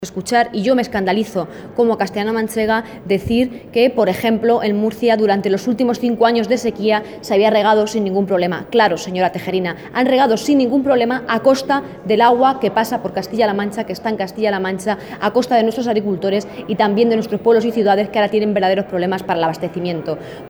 En declaraciones a medios de comunicación en el Congreso de los Diputados, Rodríguez ha sido muy dura al acusar de "falta de sensibilidad y casi de conciencia" al gobierno de España por obviar lo que está ocurriendo en otros territorios a los que deja fuera de esas medidas de apoyo para paliar la sequía.
Cortes de audio de la rueda de prensa